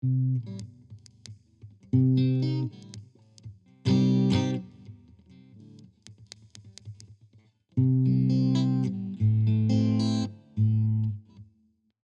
Wenn ich die Seiten berühre macht es noch so Knacks Geräusche , selbst wenn ich den Input ganz nach unten mache...